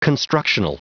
Prononciation du mot constructional en anglais (fichier audio)